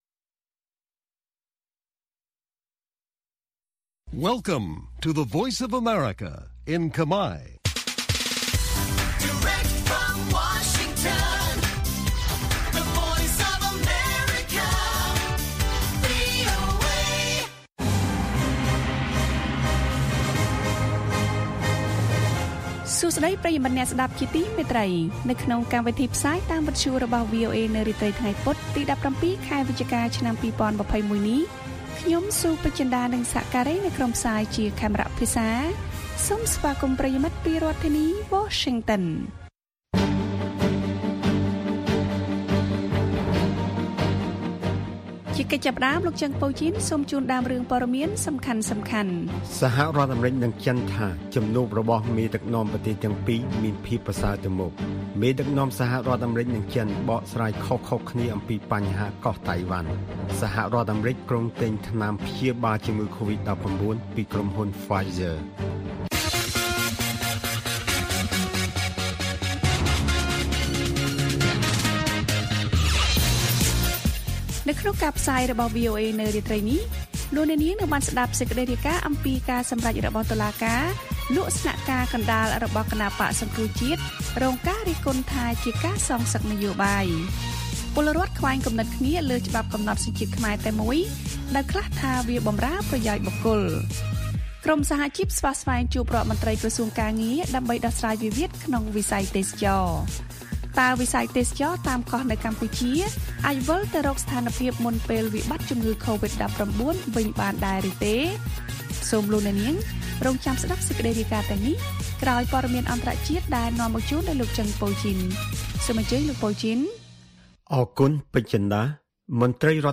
ព័ត៌មានពេលរាត្រី៖ ១៧ វិច្ឆិកា ២០២១